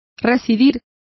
Complete with pronunciation of the translation of resided.